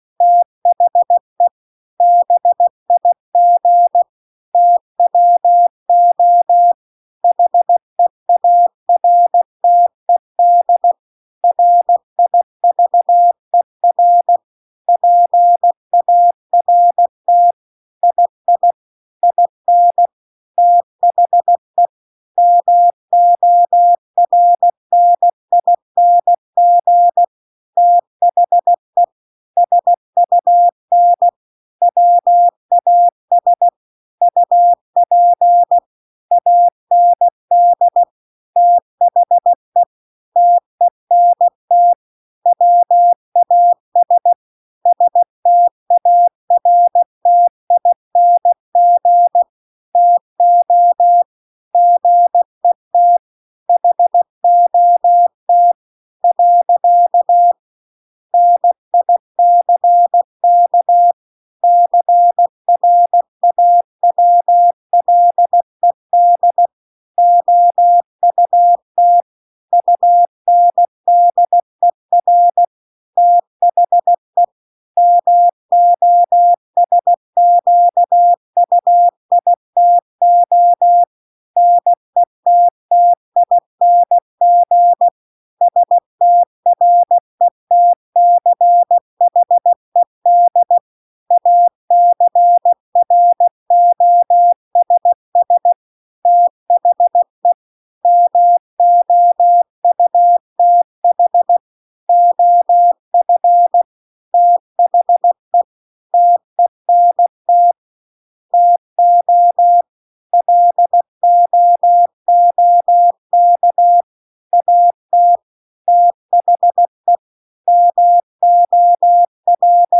CW-The-Big-Twohearted-River-2-16WPM.mp3